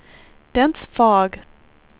WindowsXP / enduser / speech / tts / prompts / voices / sw / pcm8k / weather_49.wav
weather_49.wav